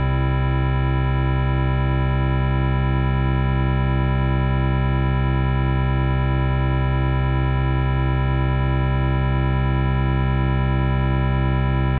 c-chord.ogg